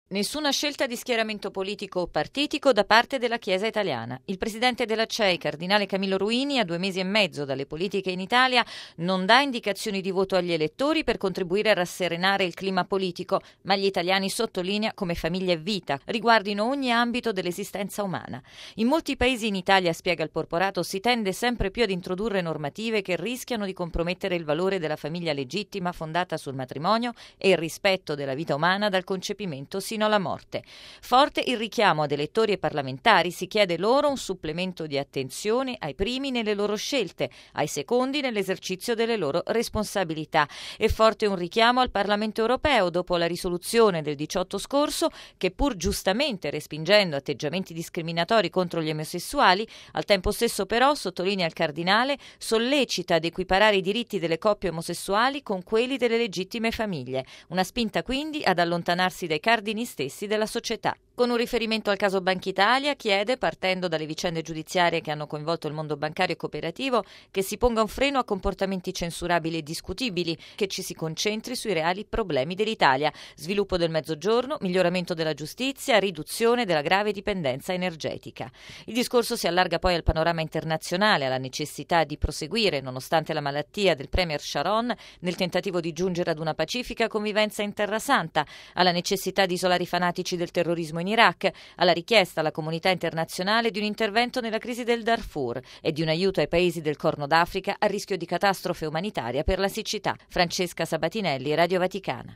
(23 gennaio - RV) Una maggiore attenzione alla famiglia e alla vita, l’ha chiesta oggi agli italiani il presidente della Conferenza episcopale italiana, cardinale Camillo Ruini nella prolusione al consiglio permanente della Cei. Il servizio